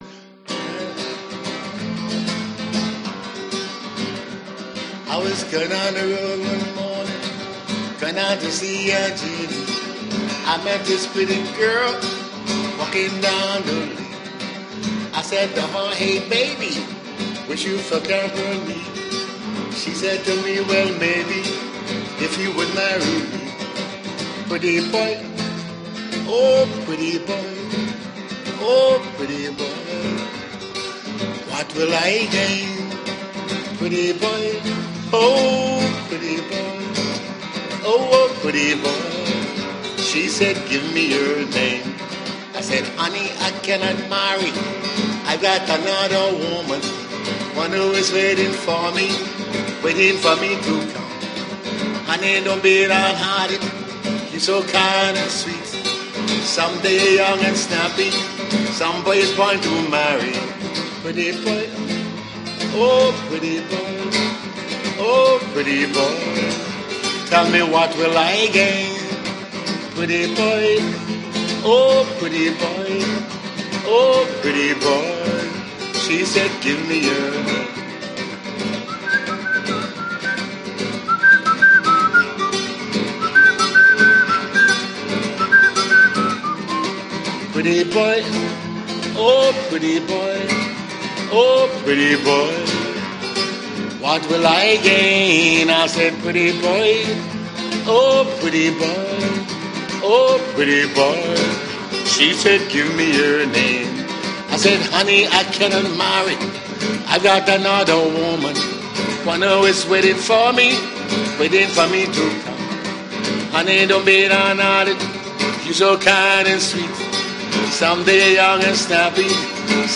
King of Soca
Guitar